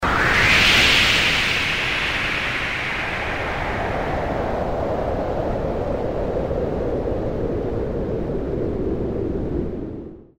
Root > sounds > weapons > hero > tusk
punch_cast.mp3